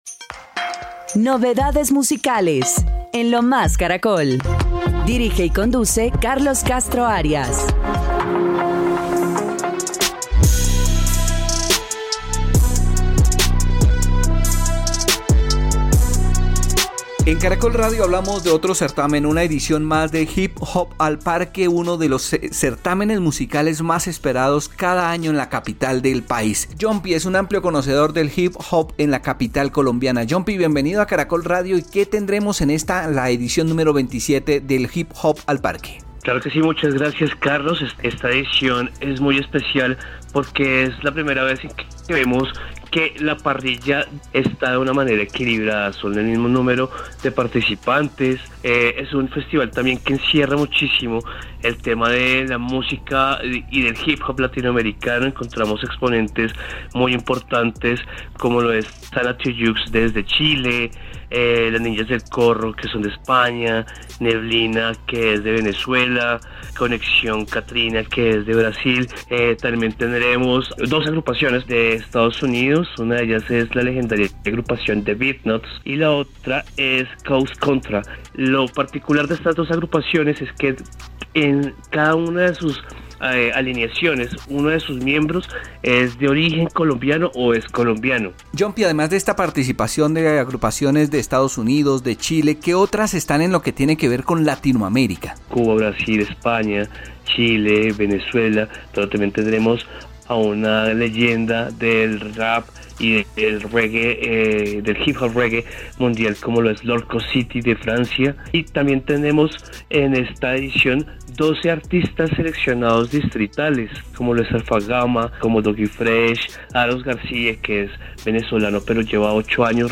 El experto en música y cultura urbana también resaltó que esta edición contará con agrupaciones provenientes de Estados Unidos, cuna del Hip Hop, cuyos integrantes tienen origen colombiano.